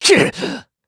Clause_ice-Vox_Damage_kr_02.wav